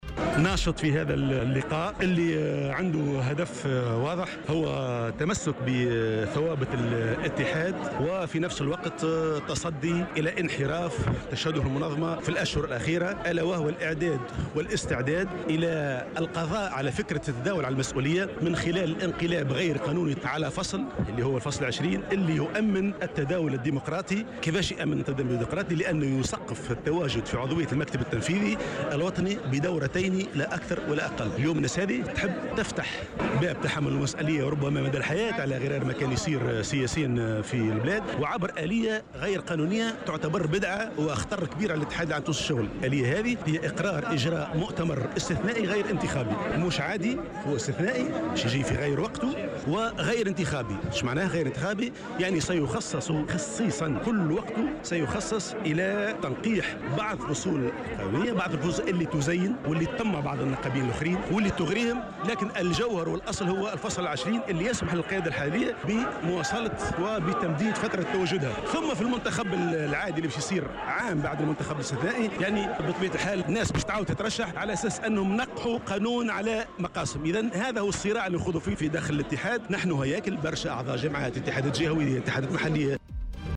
نظم اليوم عدد من النقابيين ببطحاء محمد علي الحامي بتونس العاصمة وقفة احتجاجية للتعبير عن رفضهم لمشروع تنقيح الفصل 20 من القانون الأساسي لاتحاد الشغل.